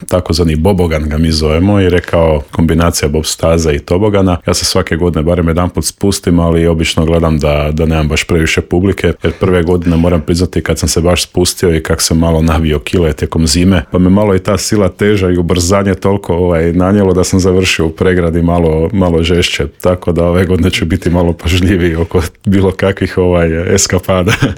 Kakva je ponuda i što sve čeka posjetitelje u Intervjuu Media servisa otkrio nam je tamošnji gradonačelnik Dario Zurovec.